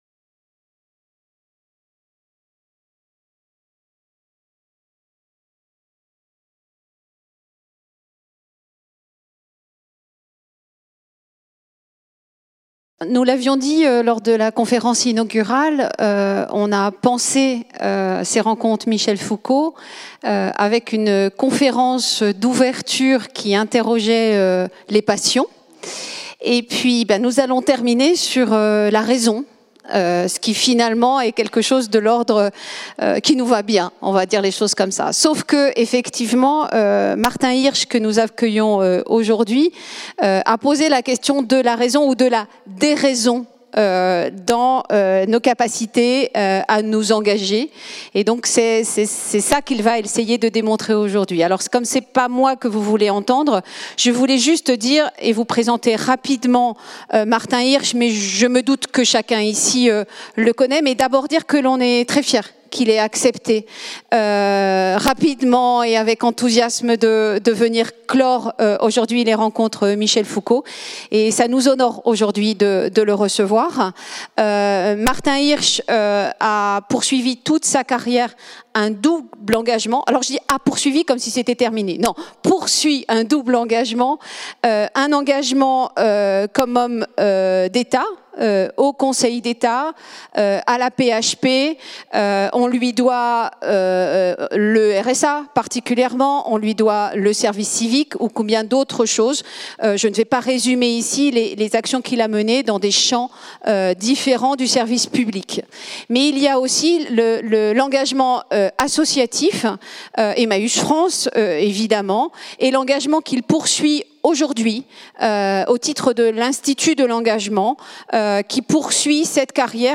Conférence de Martin Hirsch